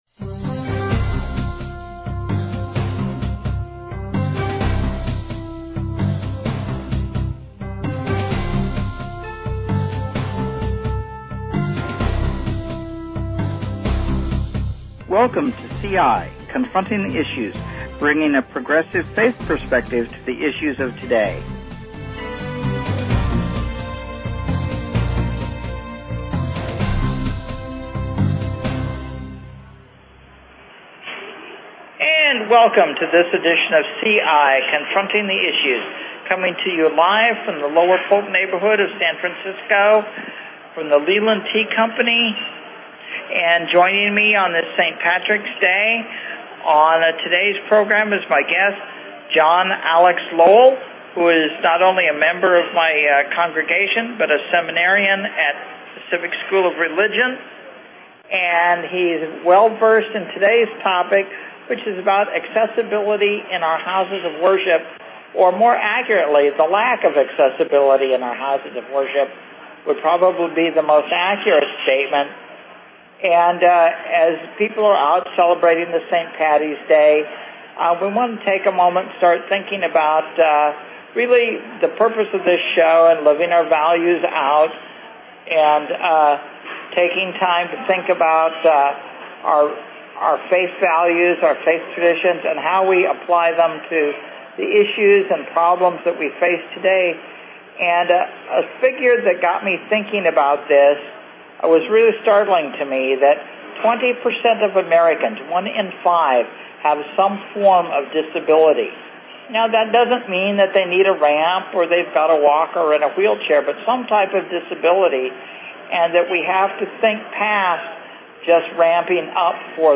Talk Show Episode, Audio Podcast, CI_Confronting_the_Issues and Courtesy of BBS Radio on , show guests , about , categorized as
Live from the Leland Tea Company, in San Francisco. Bringing a progressive faith perspective to the issues of the day.